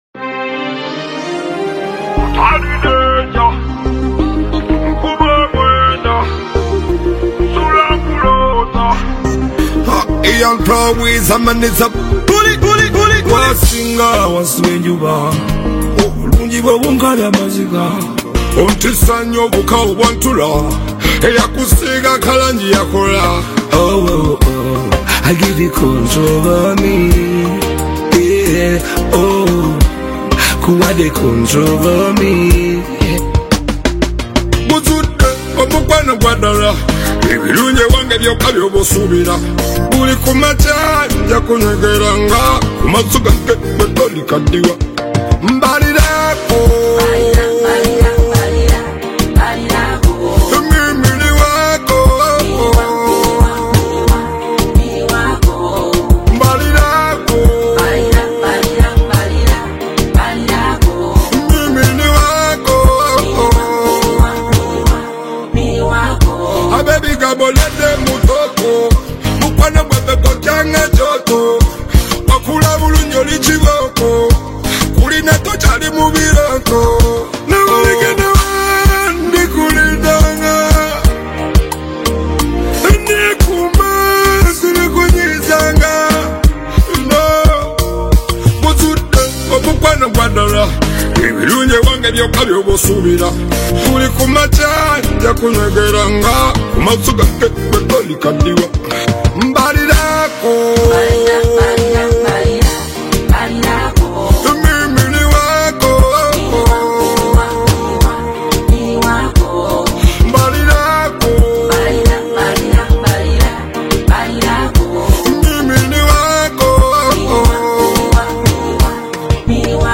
smooth Ugandan Afro-Pop/Reggae fusion single
timeless Afrobeat-inspired production